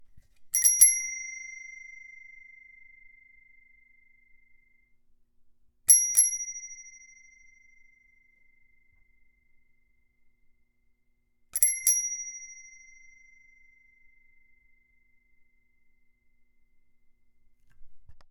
Bicycle Bell
Bell Bicycle Bike Ding Ring sound effect free sound royalty free Sound Effects